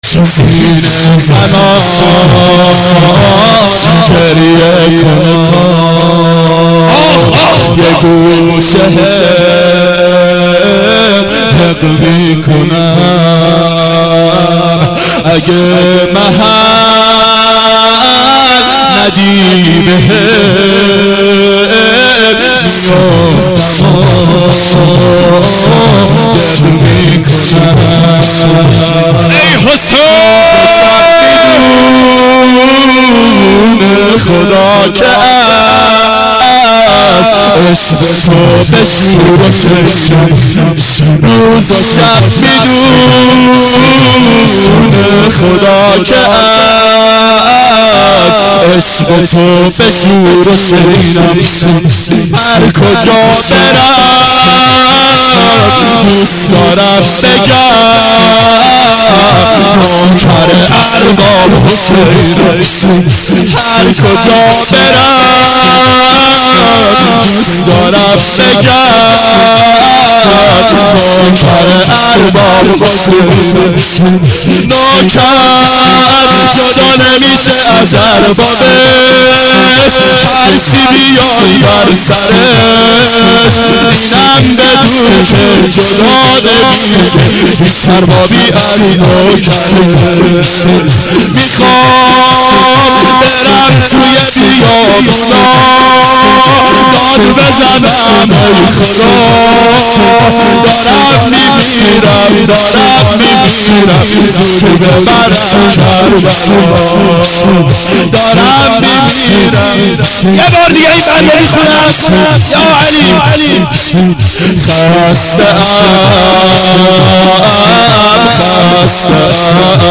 اربعین 90 هیئت متوسلین به امیرالمؤمنین حضرت علی علیه السلام